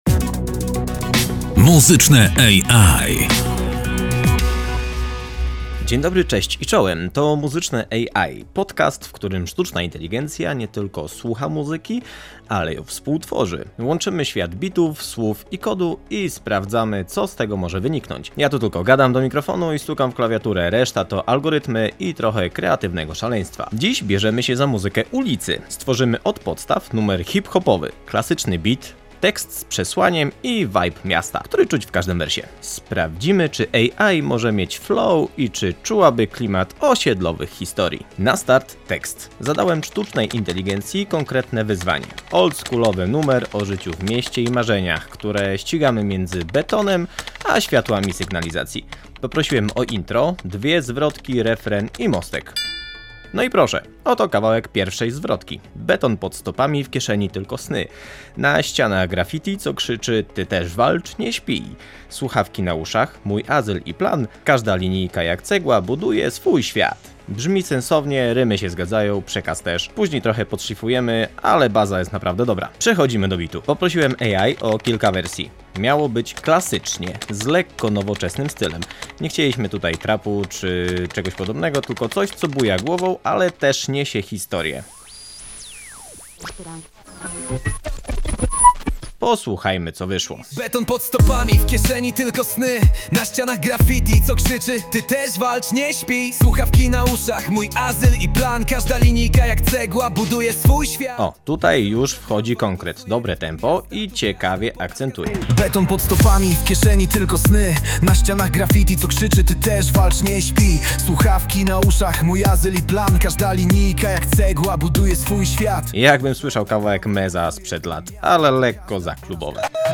Sztuczna inteligencja dostaje wyzwanie: stworzyć Hip-Hopowy numer z duszą miasta, tekstem z przekazem i klasycznym oldschoolowym vibe’em. Efekt? Powstał utwór „Miasto Słów” – historia o marzeniach, które rodzą się między betonem a światłami sygnalizacji.
AI zaproponowała tekst, kilka wersji bitu, a my wybraliśmy najlepsze elementy i dopracowaliśmy szczegóły: melodyjny mostek i delikatne scratche na koniec. Wszystko po to, by stworzyć kawałek, który buja głową, ale też niesie opowieść.
Muzycze-EjAj-Hip-Hop.mp3